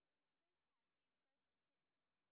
sp16_street_snr0.wav